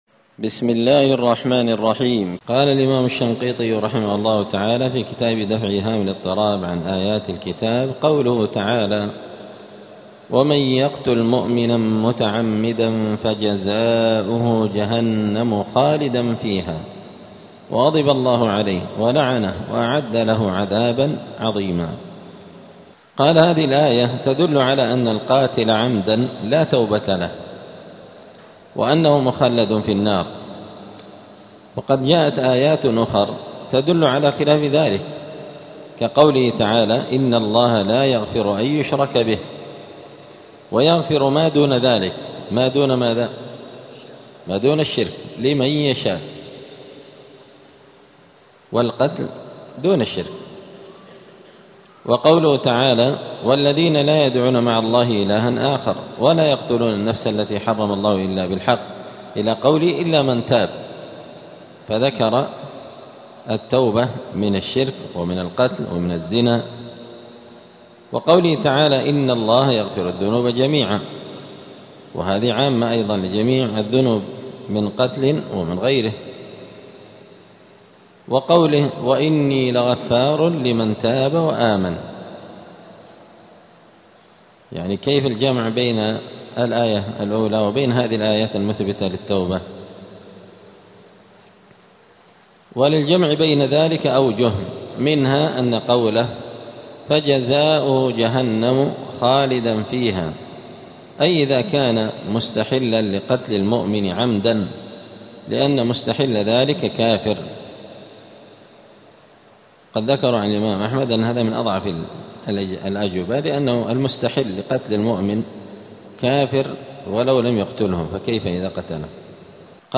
*الدرس السابع العشرون (27) {سورة النساء}.*
دار الحديث السلفية بمسجد الفرقان قشن المهرة اليمن